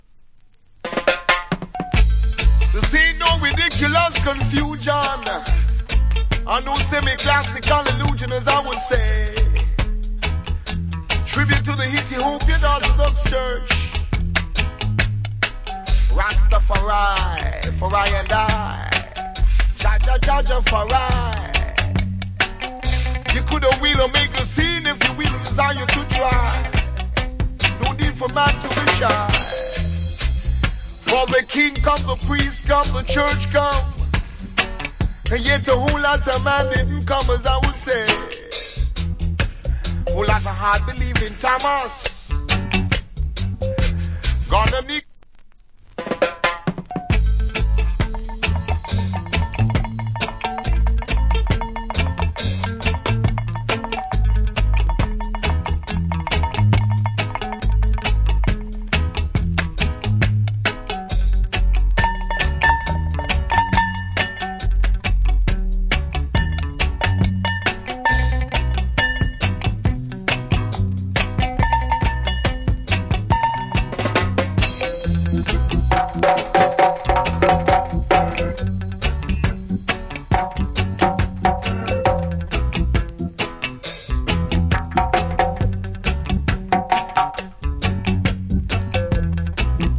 Wicked DJ.